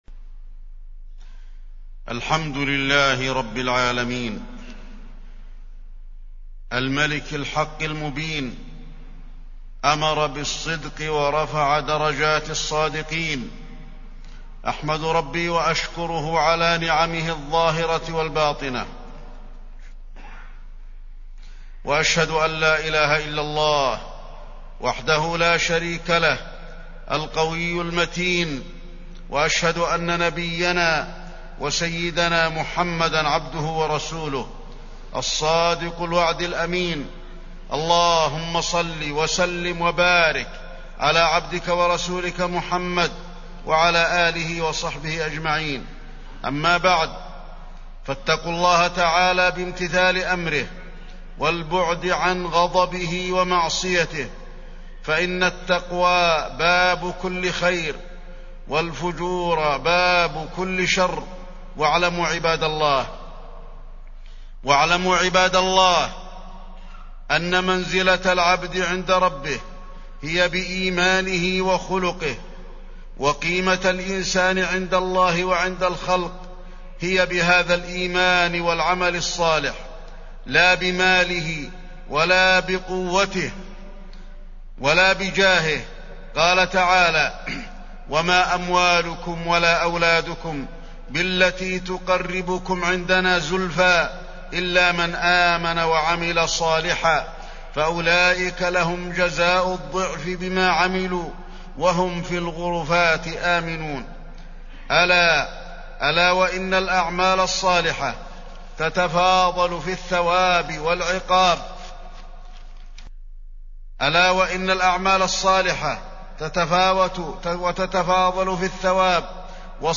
تاريخ النشر ١١ جمادى الآخرة ١٤٢٧ هـ المكان: المسجد النبوي الشيخ: فضيلة الشيخ د. علي بن عبدالرحمن الحذيفي فضيلة الشيخ د. علي بن عبدالرحمن الحذيفي الصدق